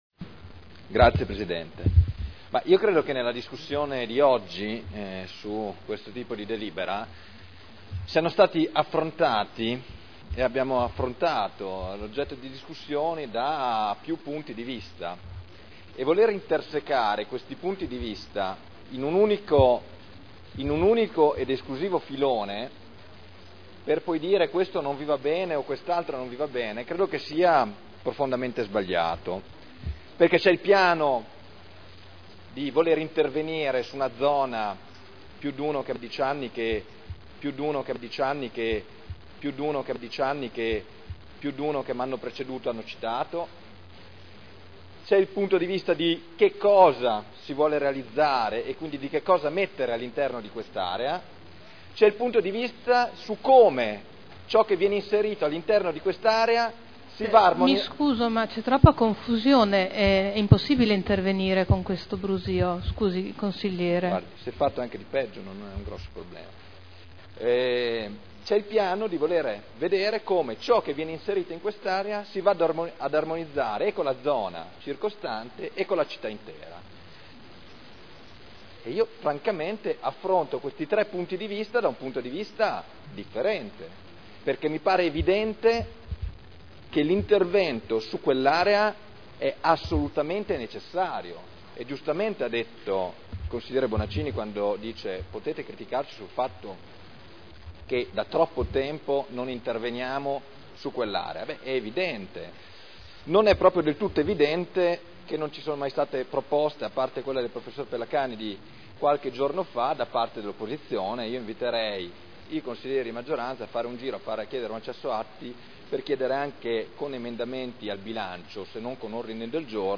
Seduta del 21/12/2009.